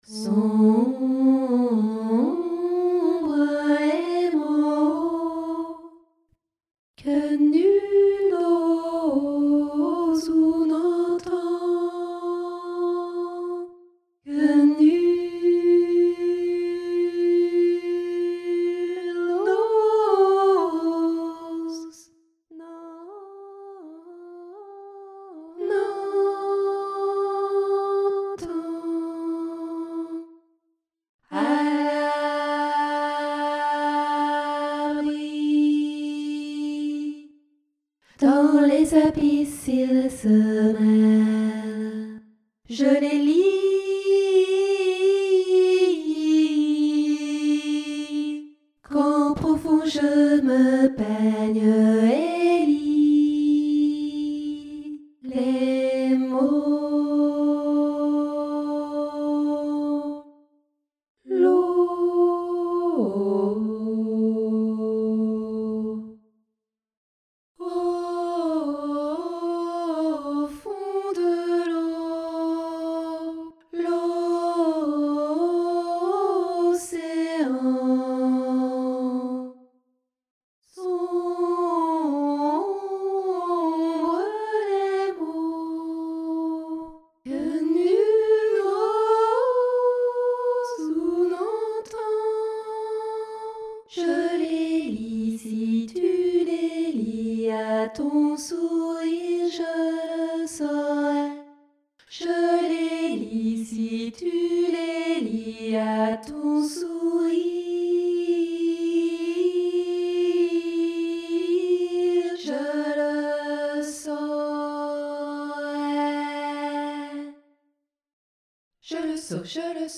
contemporain - mesure et tempo pluriels et contrastés
polyphonie 3 voix SAT
ALTO